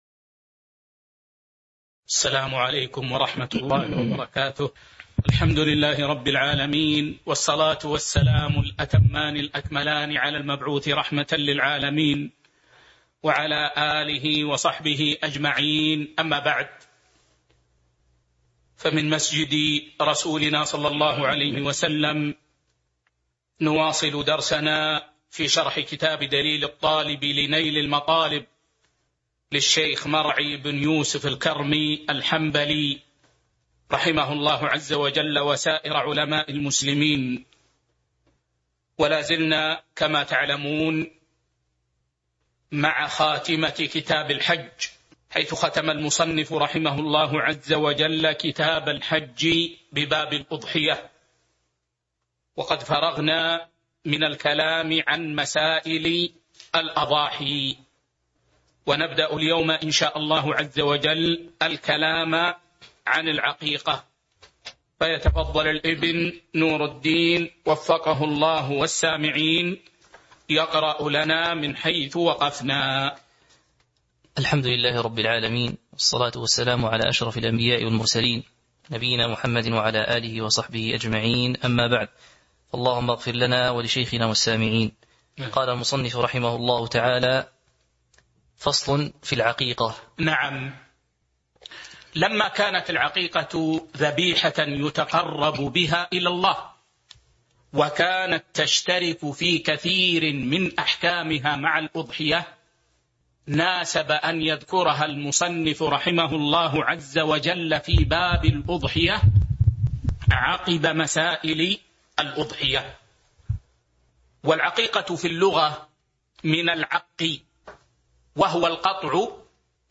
تاريخ النشر ١٥ جمادى الآخرة ١٤٤٢ هـ المكان: المسجد النبوي الشيخ